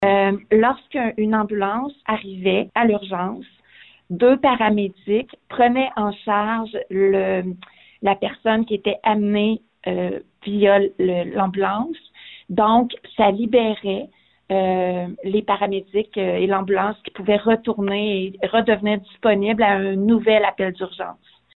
Ce problème des ambulances de la Rive-Sud qui doivent attendre dans les garages en raison des engorgements au Centre hospitalier affilié universitaire régional de Trois-Rivières (CHAUR) a donc refait surface lundi soir lors de la séance du conseil.
La mairesse, Lucie Allard, a indiqué que pendant la pandémie, ça se déroulait mieux.